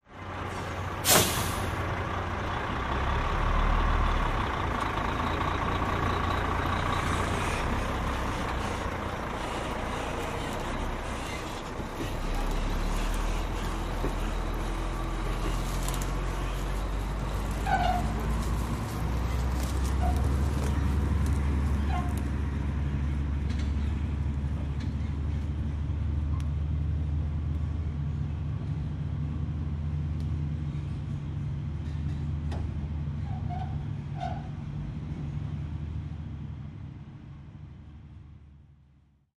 tr_dieseltruck_away_02_hpx
Diesel truck idles and then drives off with brake releases. Vehicles, Truck Engine, Motor